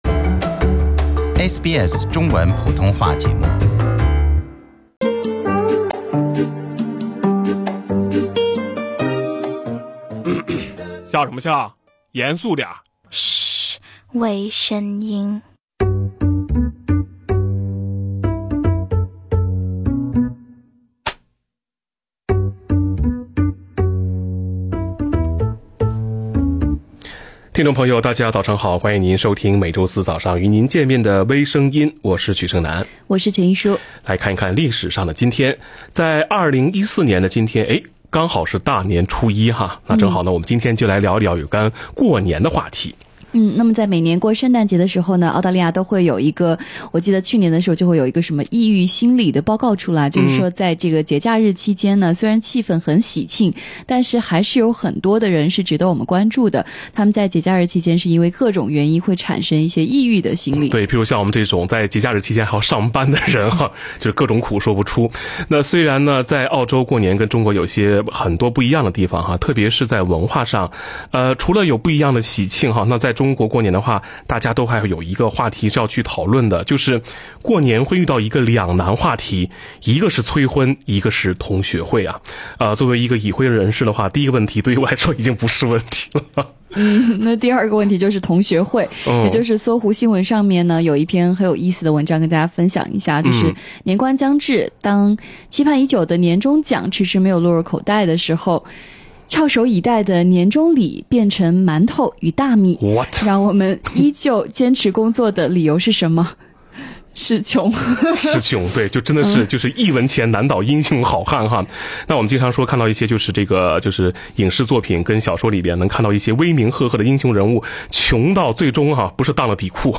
另类轻松的播报方式，深入浅出的辛辣点评；包罗万象的最新资讯；倾听全球微声音。 - 农历新年两大难题：同学会和催婚 - 悉尼农历新年精彩庆祝活动 -2018年新生婴儿取名排名前十位名字公布